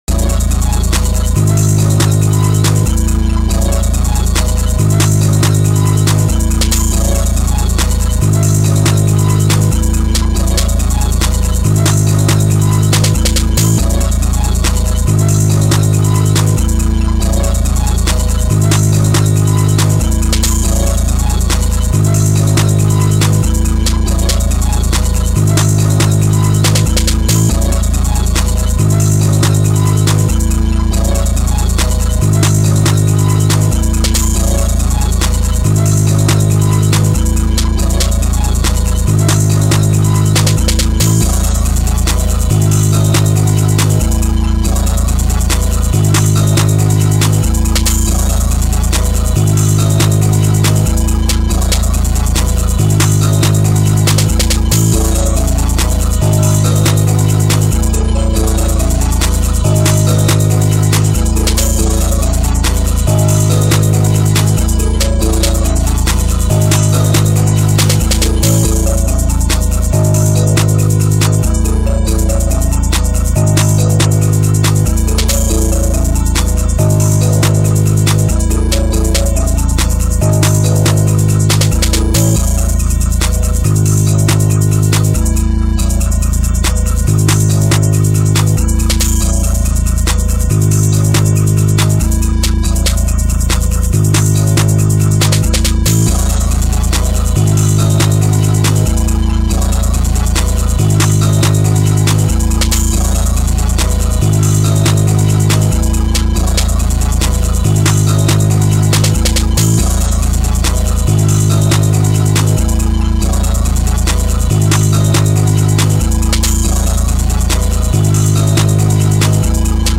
Alright, opinions on this next beat-